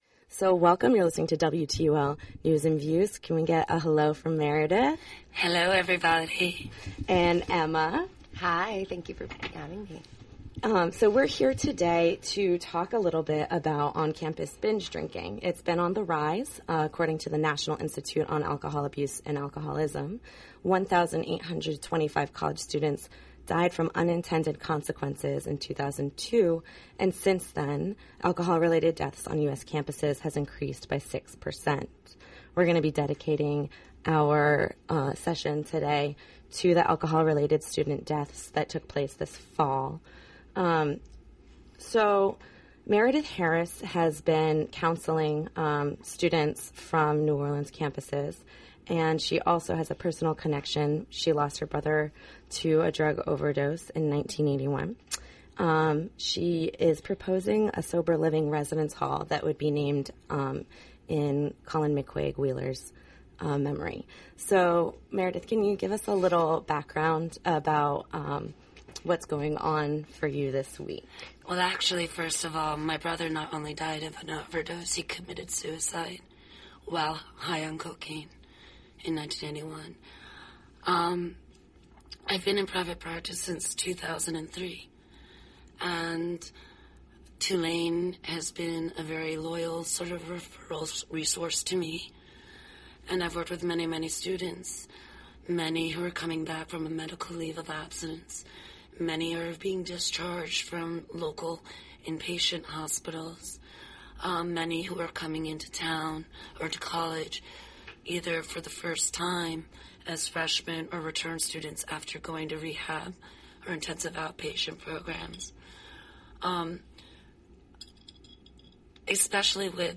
40min Counselor, parent, and student discuss mental-health and substance-use -related fatalities on Tulane's uptown campus
Three in-studio voices join us to respond to the mental-health and substance-use -related fatalities in the Tulane Community